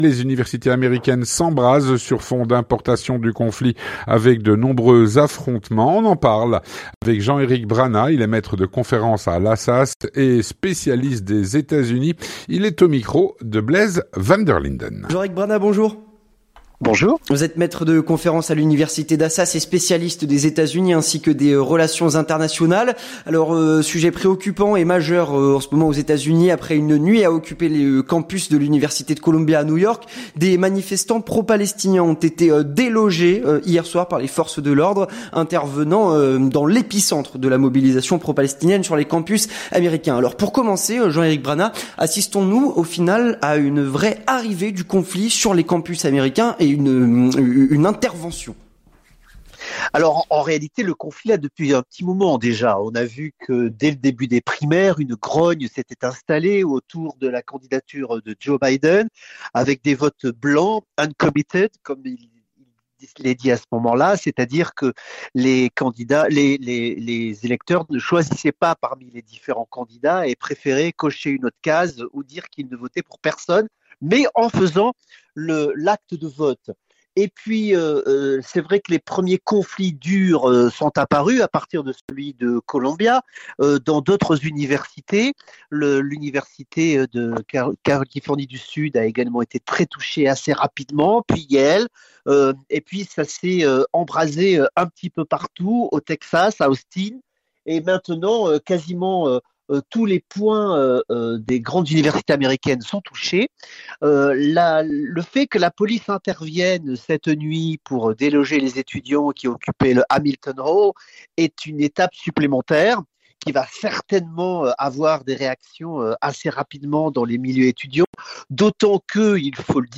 L'entretien du 18H - Les universités américaines s’embrasent sur fond d’importation du conflit.